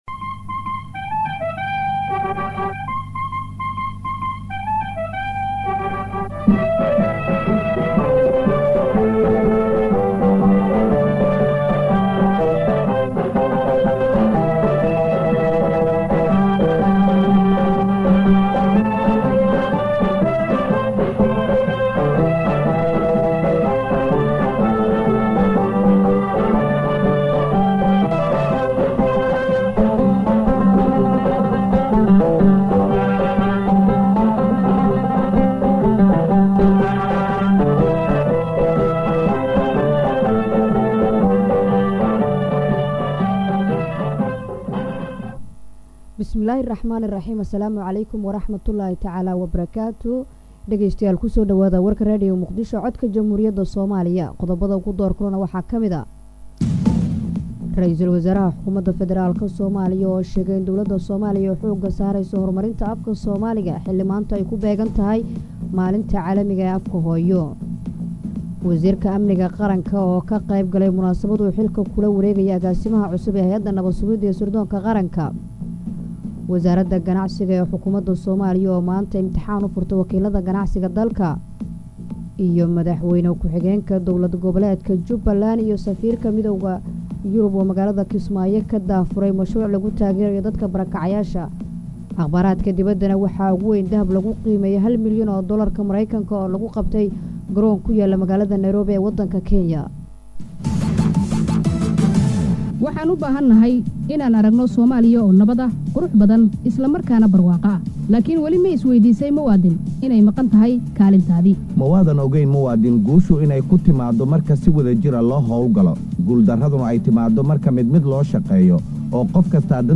Dhageyso Warka Habeen ee Radio Muqdisho